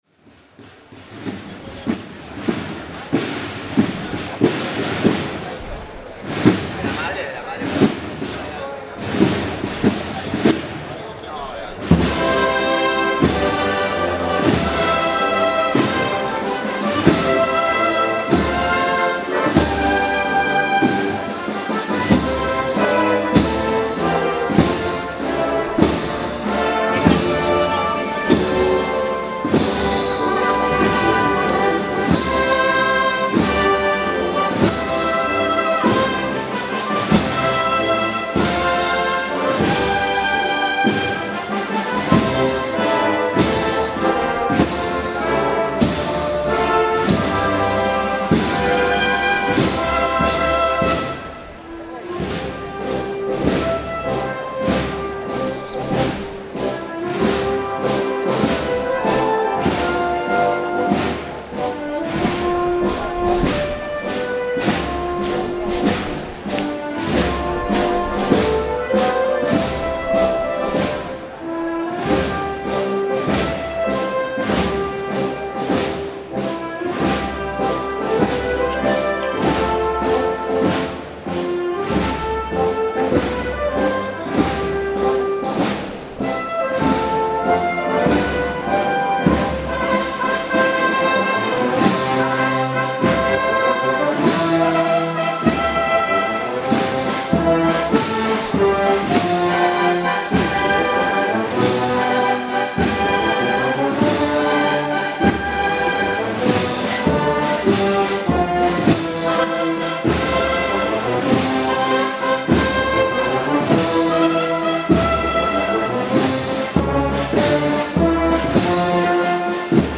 Marchas procesionales
Para Bandas de Música